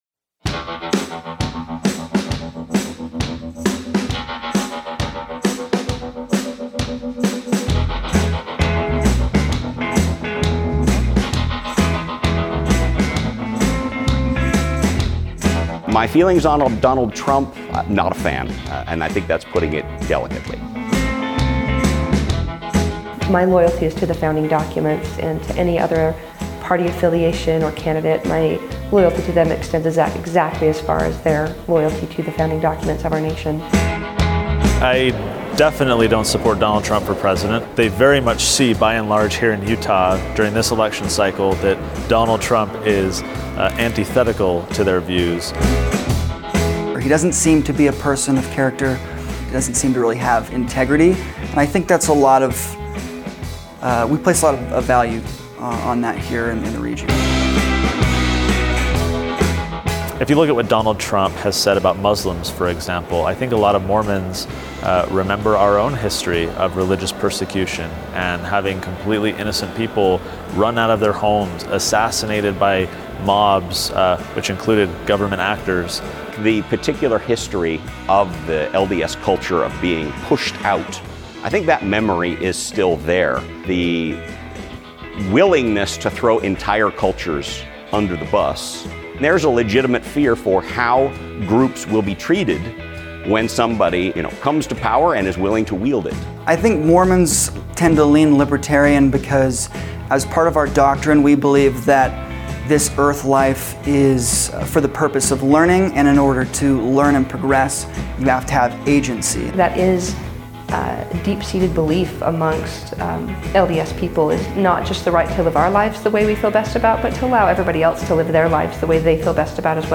Reason TV asked these questions of several former Republican voters in Utah, many of whom drew surprising connections between the teachings of the Latter Day Saints church and the libertarian philosophy.